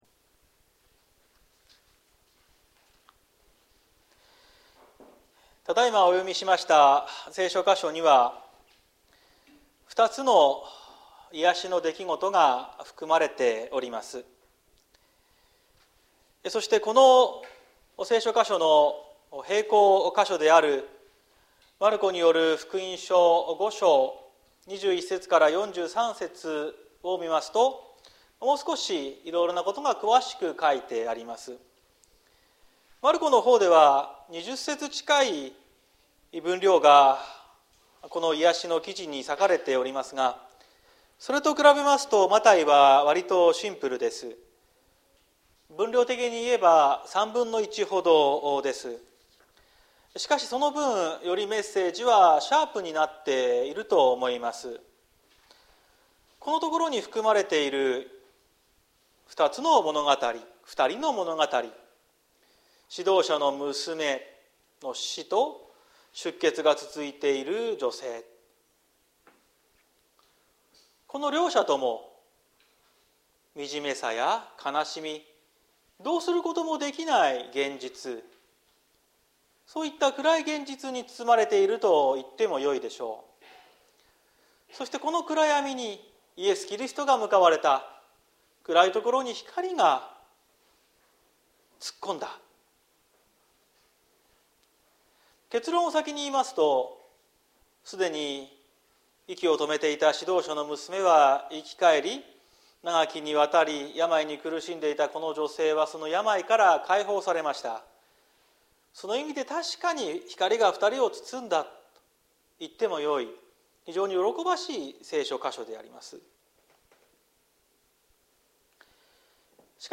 2023年09月03日朝の礼拝「元気になりなさい」綱島教会
説教アーカイブ。